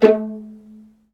VIOLINP BN-R.wav